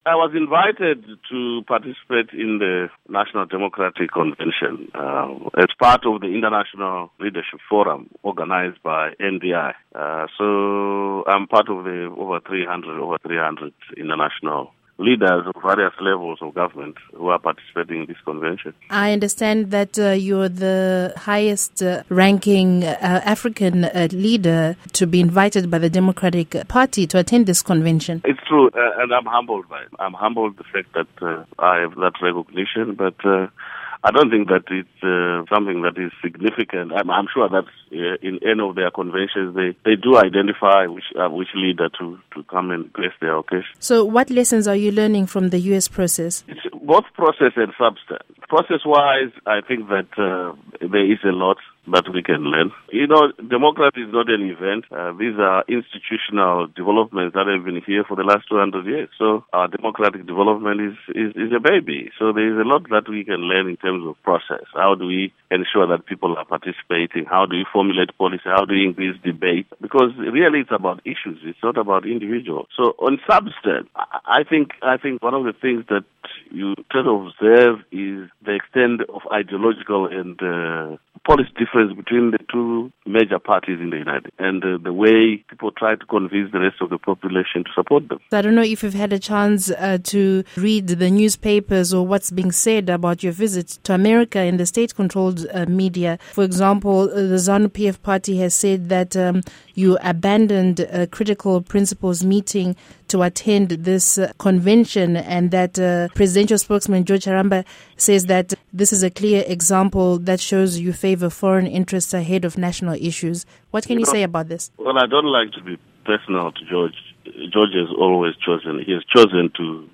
Interview Prime Minister Morgan Tsvangirai on DNC